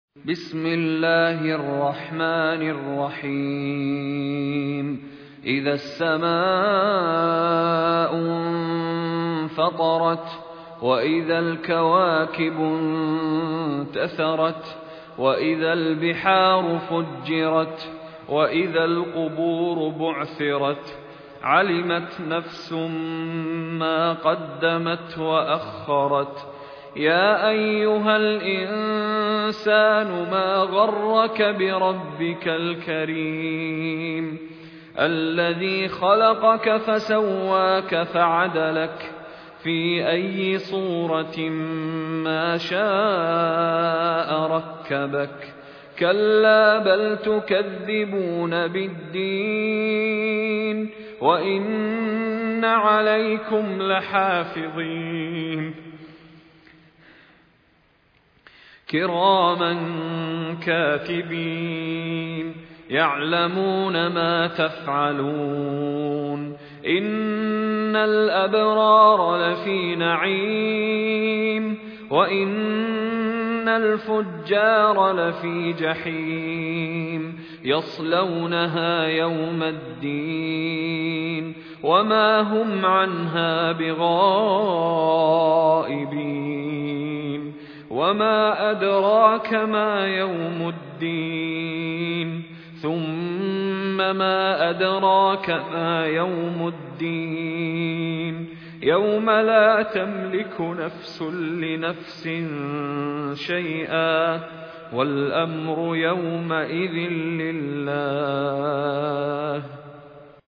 مشاري بن راشد العفاسي المصحف المرتل - حفص عن عاصم - الانفطار